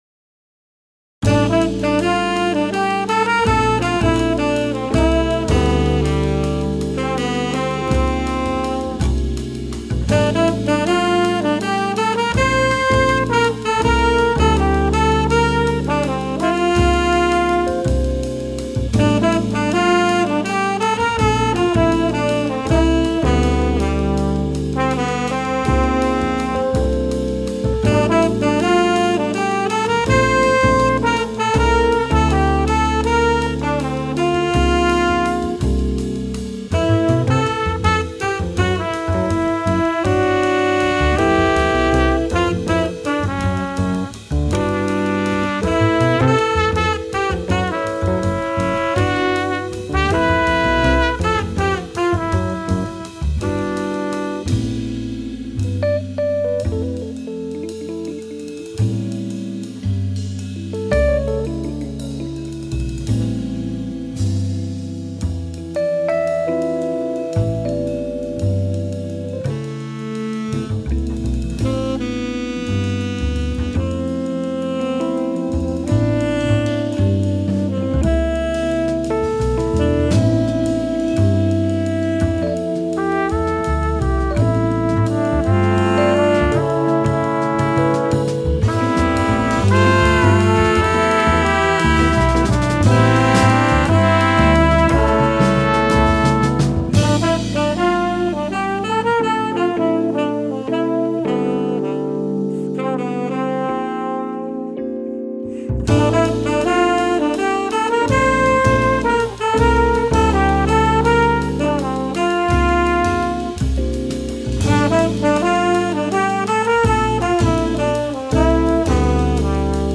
bass
trumpet
saxophone
trombone
drums